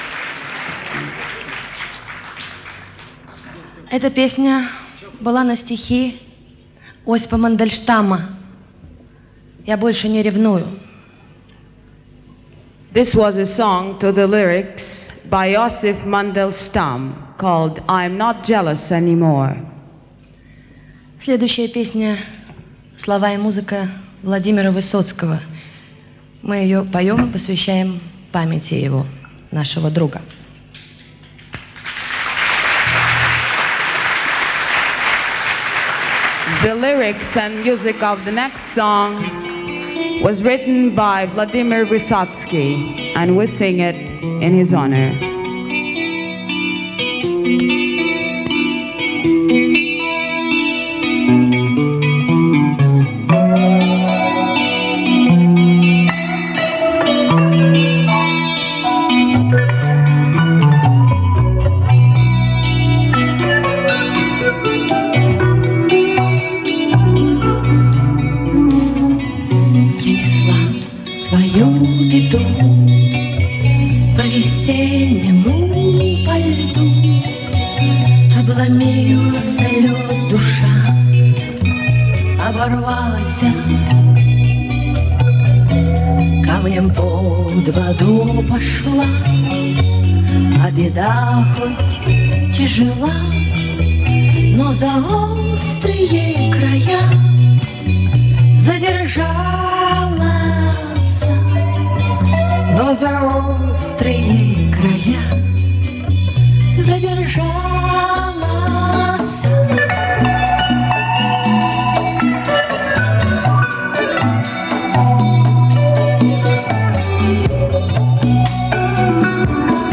с концерта для гостей Олимпиады-80